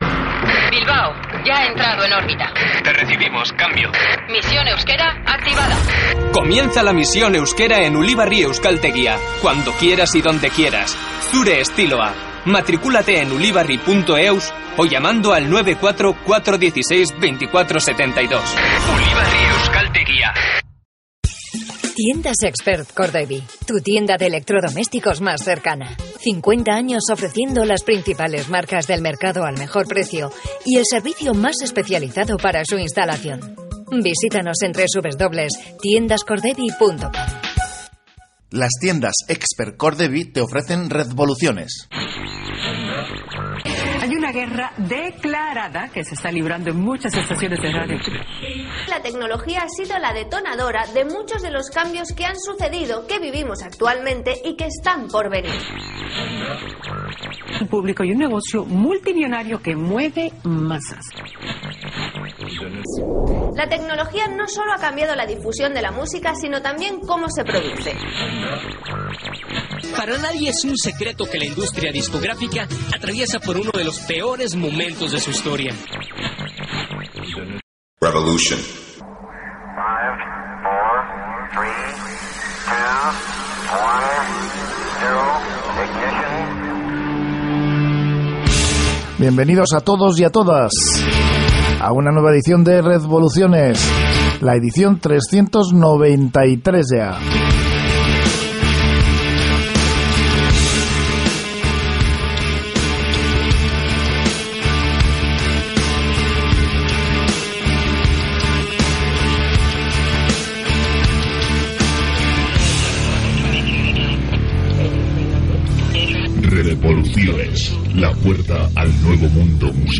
Programa sobre música, internet y tecnología en Onda Vasca.